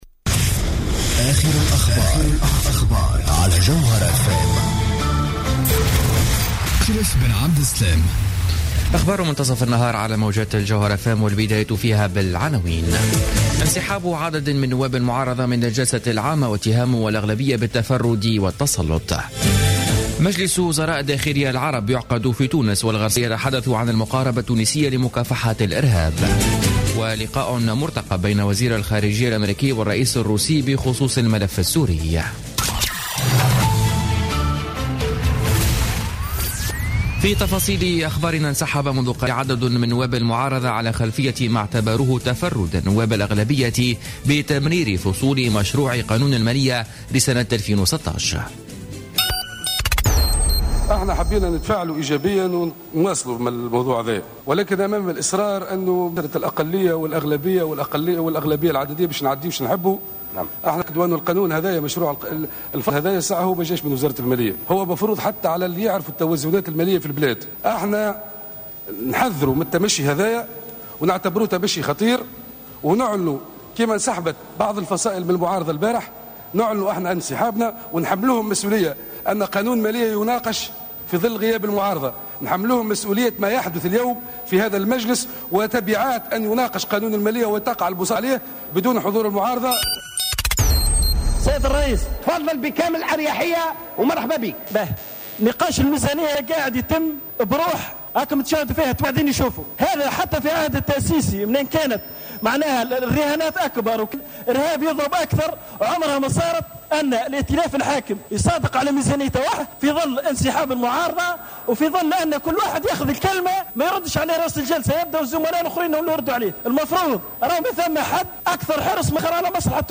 نشرة أخبار منتصف النهار ليوم الأربعاء 09 ديسمبر 2015